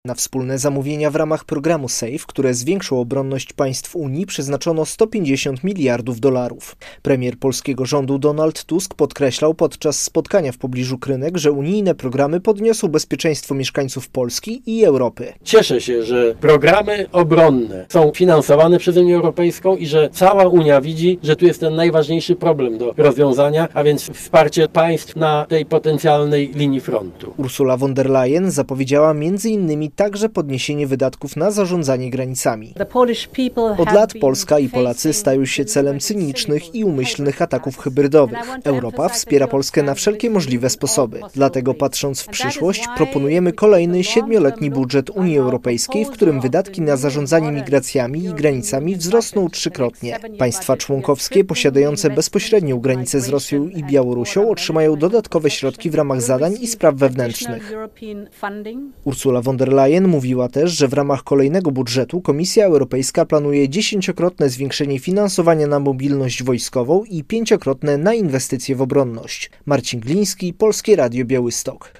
Polska będzie największym beneficjentem programu zakupowego SAFE - relacja